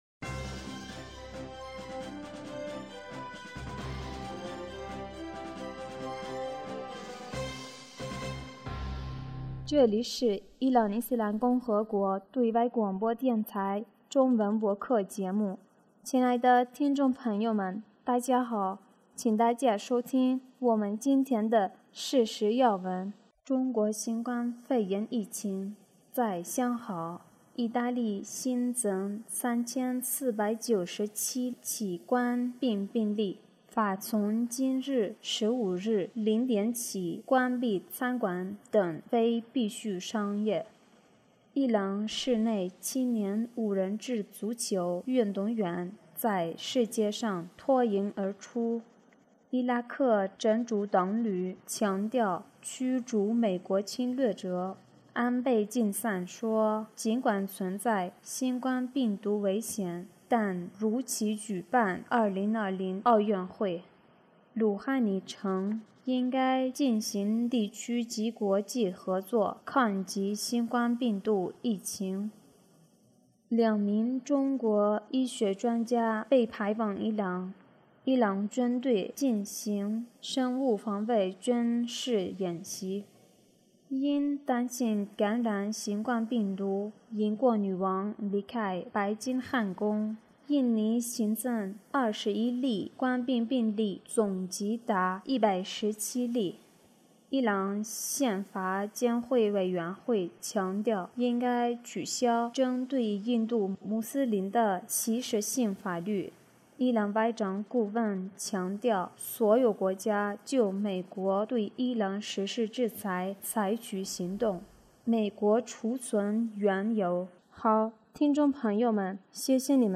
2020年 3月15日 新闻